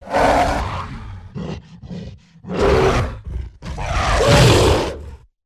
gorilla-roar-sound
Gorilla roar sound - İndir Materyali İndir Bu materyalin etiketi henüz eklenmemiştir.